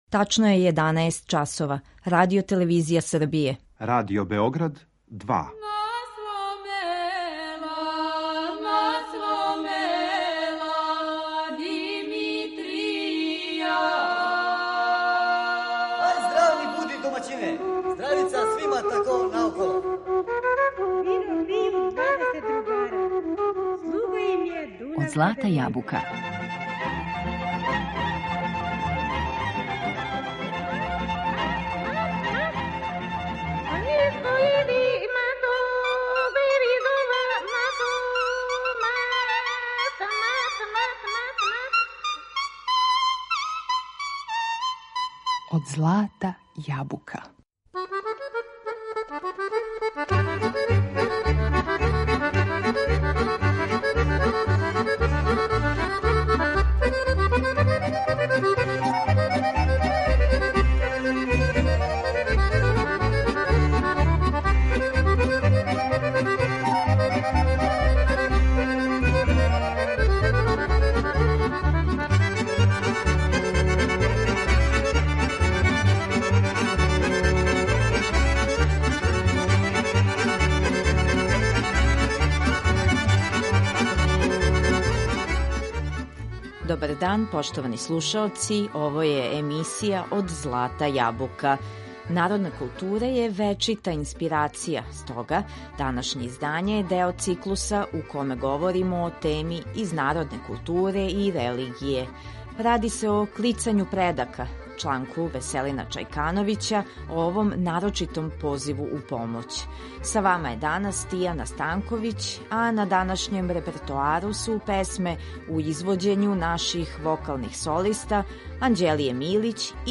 Овај посебан вид призивања мртвих у помоћ, клицање или поклич, био је обавезан током борбе, нарочито када је опасност била највећа. На репертоару су најлепше песме наших вокалних солиста уз пратњу Народног оркестра.